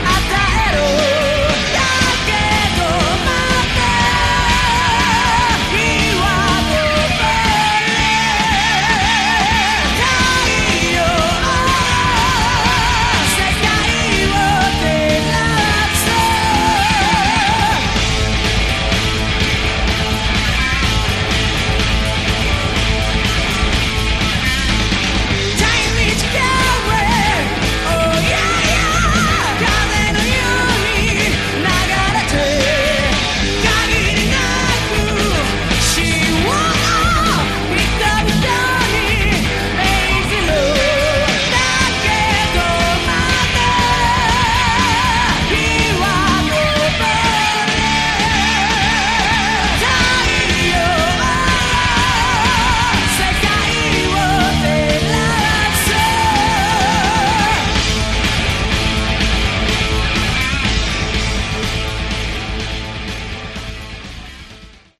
Category: Hard Rock
bass
guitar
vocals
drums